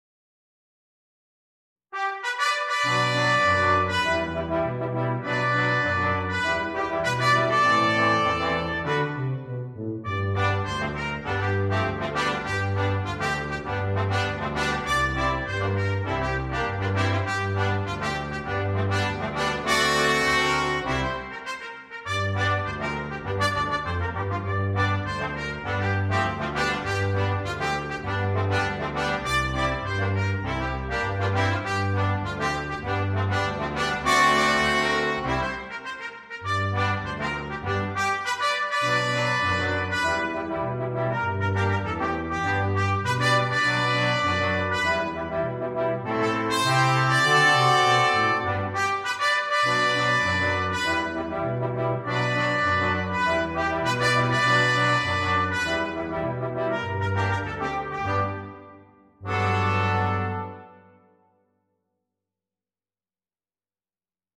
для брасс-квинтета.